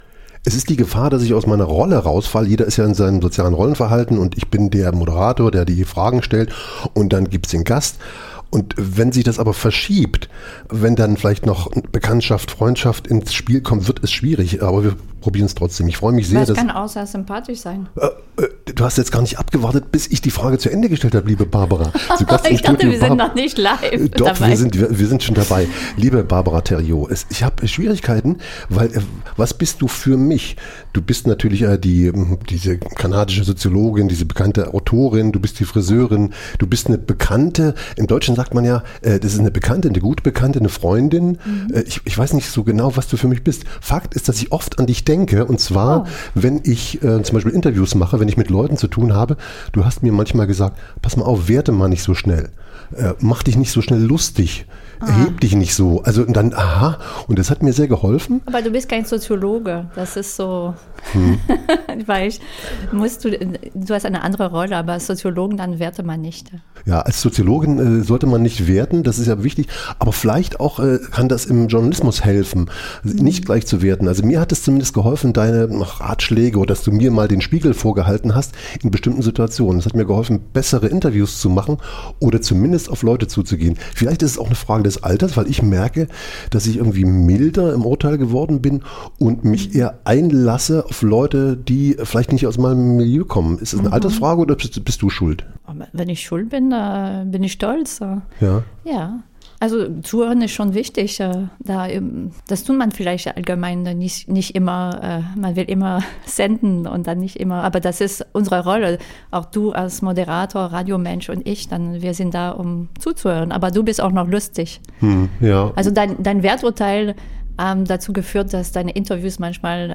Zwischen Suhl und Montréal wird klar: Stimmung ist ansteckend � und Zukunft ein Gefühl. Ein Gespräch über Nostalgie, Meckern als Sozialtechnik und die Kunst, trotzdem zuzuhören.